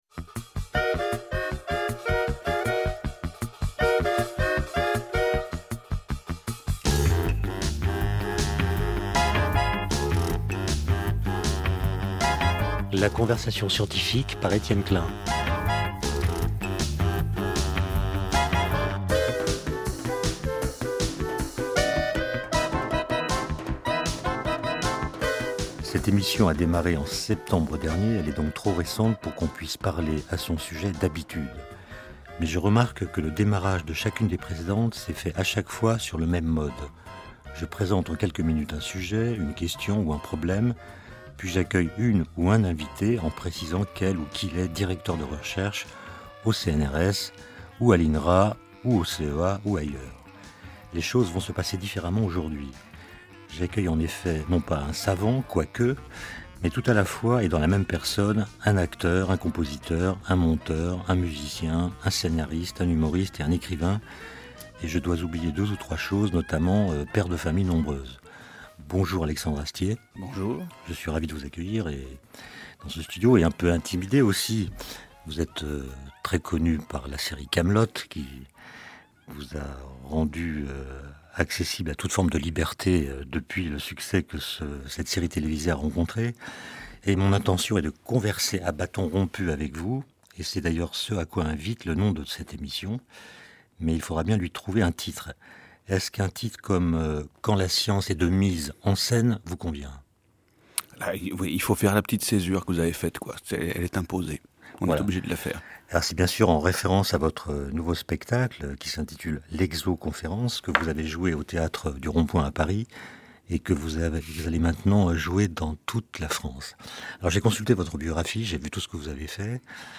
Alexandre Astier et Étienne Klein papotent sciences, physique quantique, extraterrestres (des commentaires qui peuvent d'ailleurs s'appliquer aux religions), exoplanètes, d'Einstein et j'en passe.